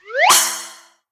jackpot_price_fly_land_01.ogg